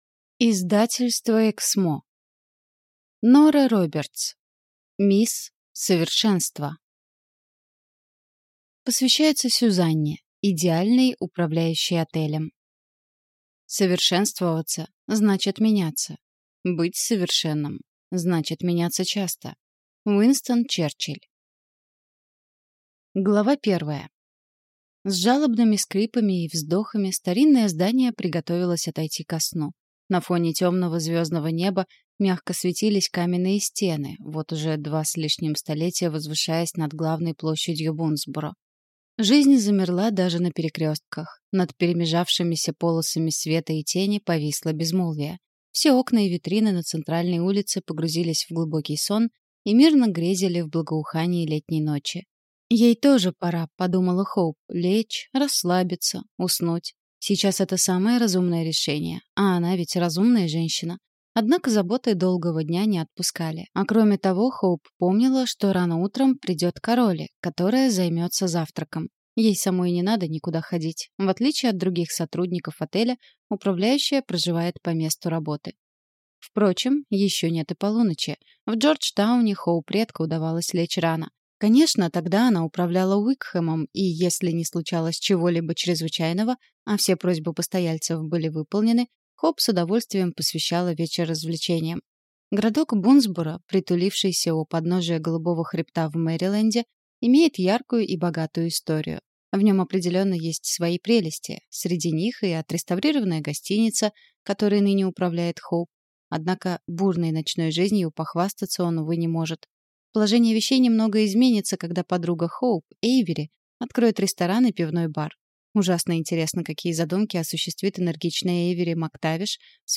Аудиокнига Мисс Совершенство | Библиотека аудиокниг